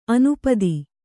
♪ anupadi